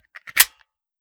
9mm Micro Pistol - Loading Magazine 001.wav